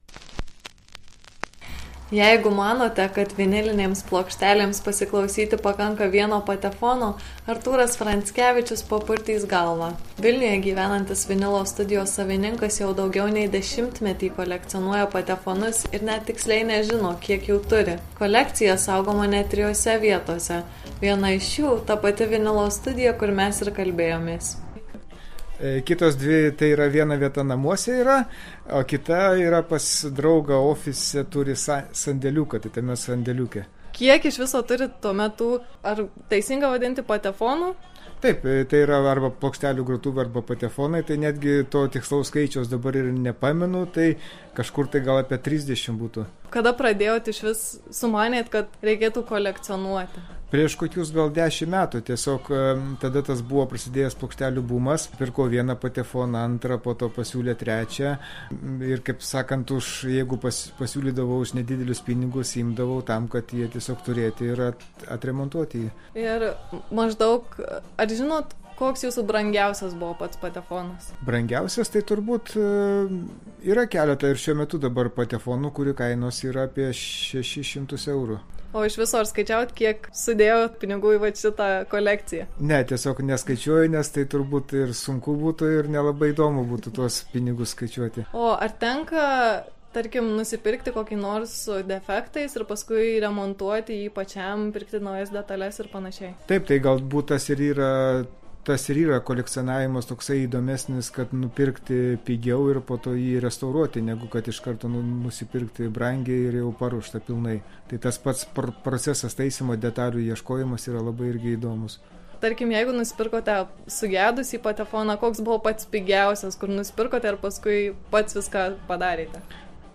Kiek kainuoja toks hobis, kam reikia keturių vienodų patefonų ir kur juos visus sudėti? Tai ir dar daugiau – reportaže. http